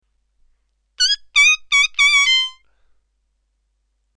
diatonic harmonica